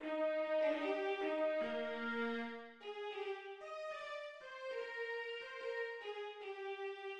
Symphony No. 19 in E-flat major, K. 132, is a symphony composed by Wolfgang Amadeus Mozart in July 1772.[1]
The symphony is scored for two oboes, four horns (two in E-flat high, two in E-flat low), and strings.
\relative c' { \set Staff.midiInstrument = #"string ensemble 1" \tempo "Allegro" \set Score.tempoHideNote = ##t \tempo 4 = 150 \key es \major es4.\f\trill d16 es g4 es | bes2 r4 as'8.(\p g16) | g8-. r es'8.( d16) d8-. r c8.( bes16) | bes4.( c8) bes4-. as-.